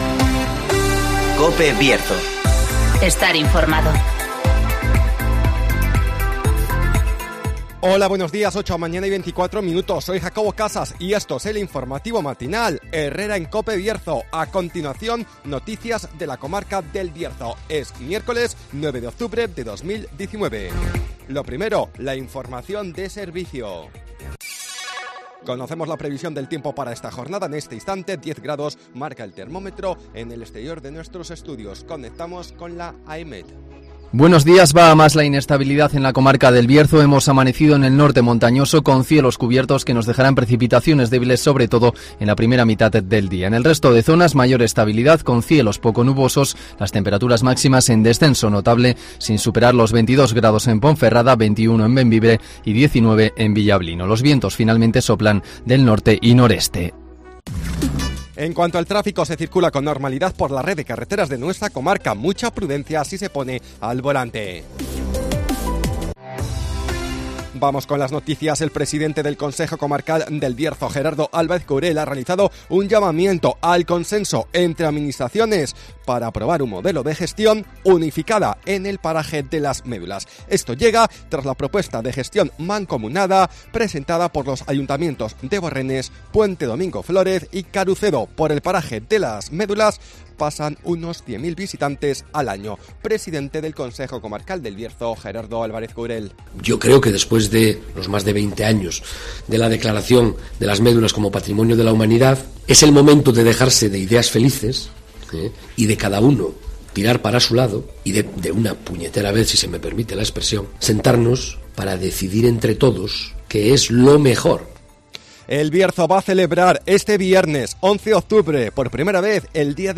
INFORMATIVOS BIERZO
Conocemos las noticias de las últimas horas de nuestra comarca, con las voces de los protagonistas